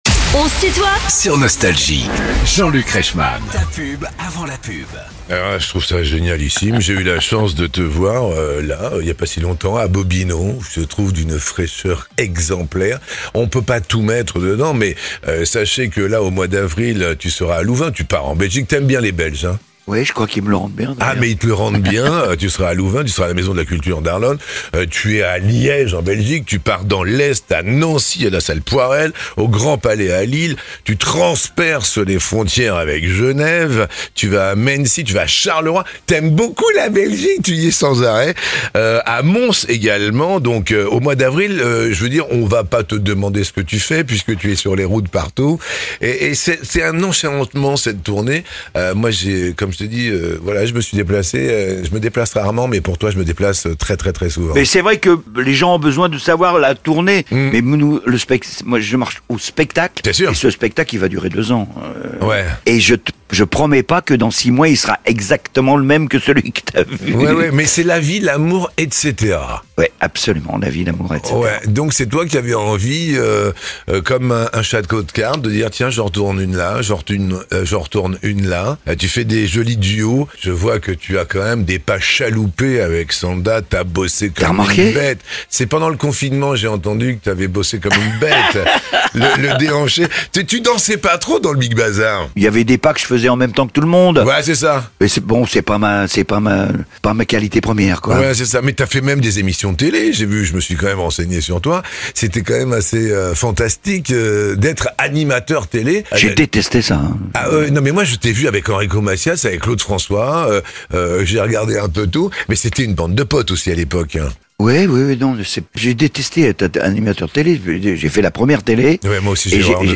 Michel Fugain invité de "On se tutoie ?..." avec Jean-Luc Reichmann (L'intégrale - Partie 2) ~ Les interviews Podcast
Michel Fugain, l'icône de la chanson française, est l’invité de "On se tutoie ?..." avec Jean-Luc Reichmann pour présenter son dernier album La Vie, l’amour, etc… et revenir sur plus de 60 ans de carrière.